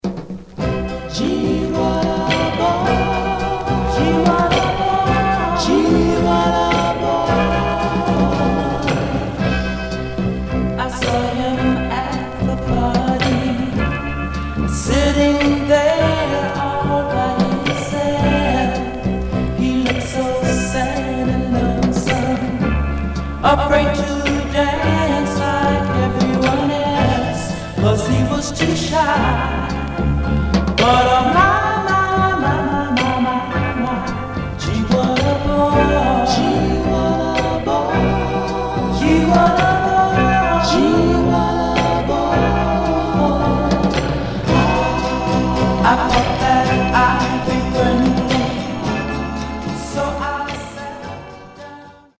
Mono
Black Female Group